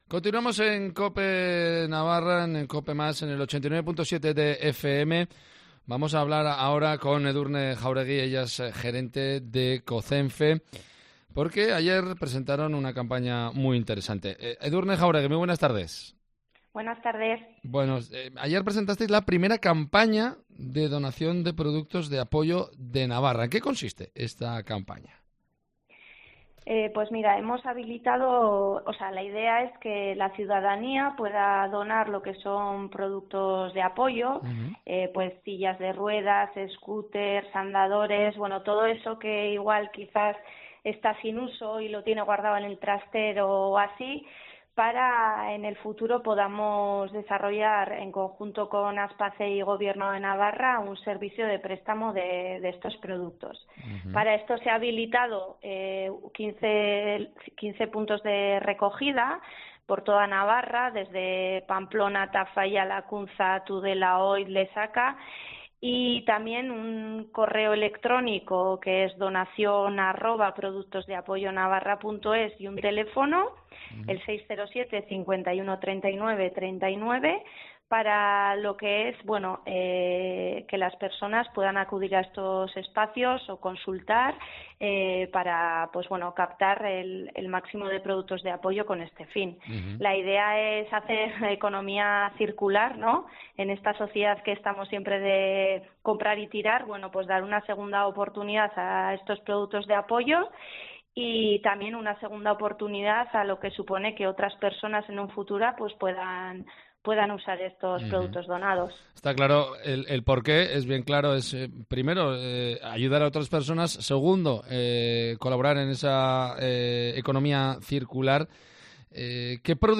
Pamplona